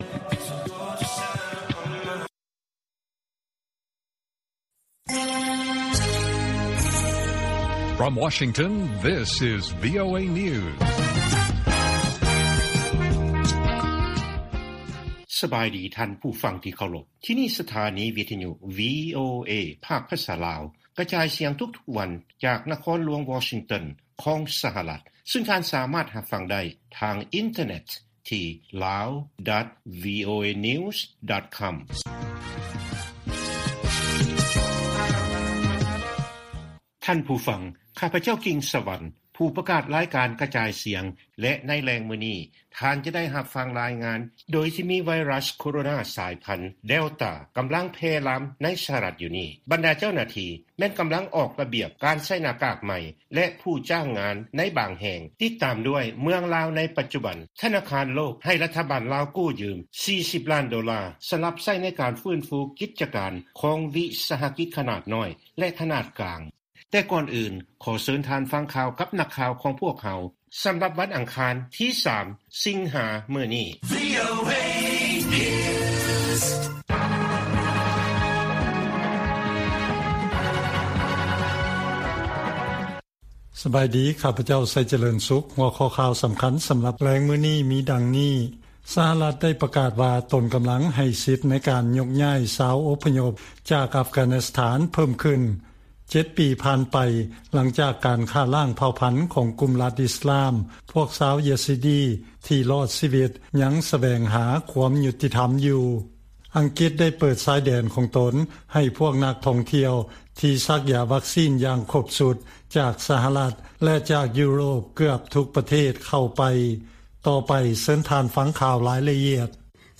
ລາຍການກະຈາຍສຽງຂອງວີໂອເອ ລາວ: ສະຫະລັດໄດ້ປະກາດວ່າຕົນກຳລັງໃຫ້ສິດໃນການຍົກຍ້າຍຊາວອົບພະຍົບຈາກອັຟການິສຖານເພີ້ມຂຶ້ນ